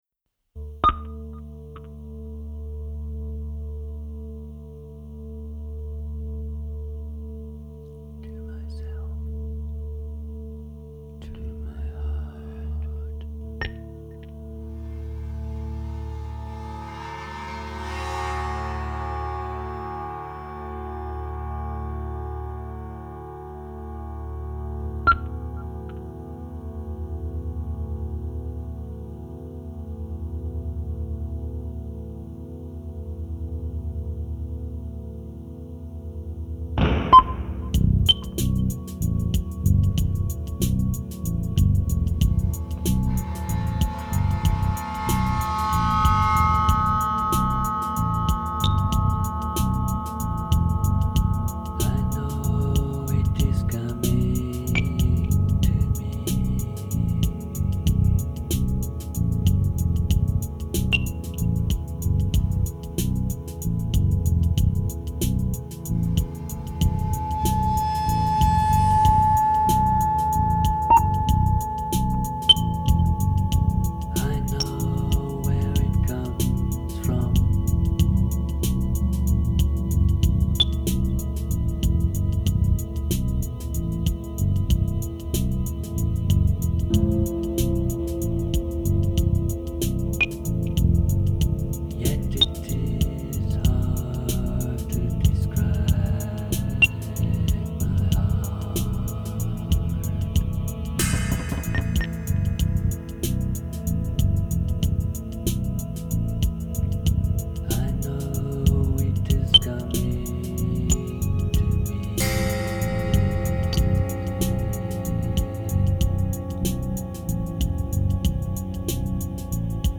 Wir haben dann beschlossen, die Tracks zu besingen und zu beklatschen und natürlich unveröffentlicht zu lassen.
Samples, Keyboards
Gesang und Percussion
Bass und Drum Programming